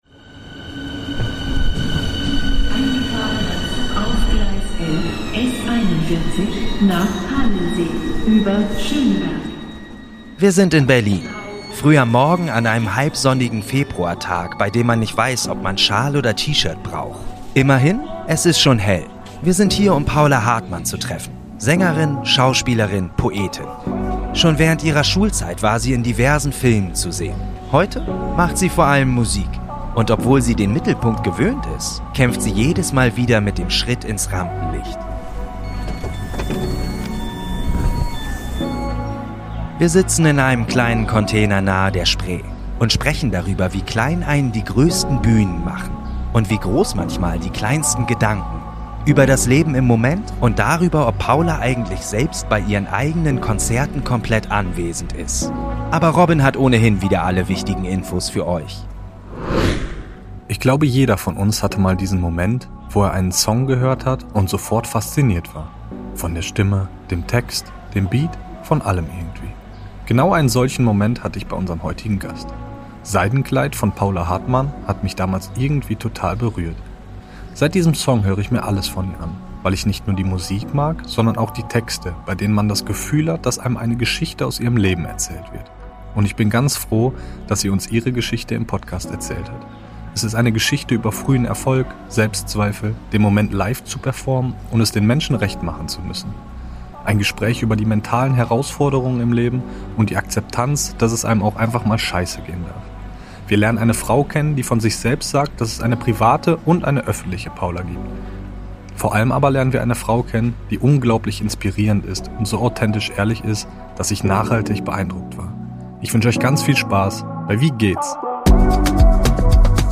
Robin ist der Moderator, Tommi der Interviewte.